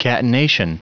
Prononciation du mot catenation en anglais (fichier audio)